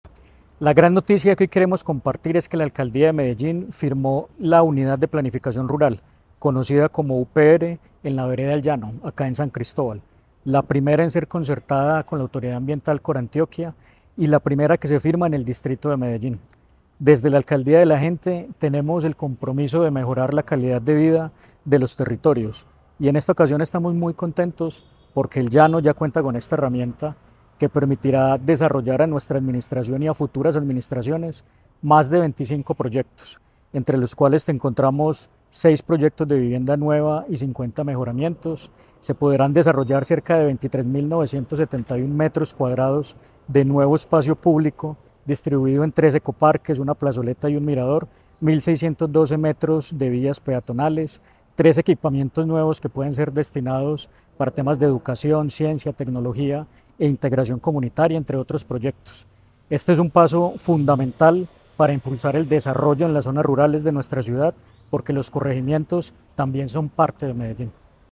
Declaraciones del gerente de Corregimientos, Andrés Felipe López Vergara
Declaraciones-del-gerente-de-Corregimientos-Andres-Felipe-Lopez-Vergara.mp3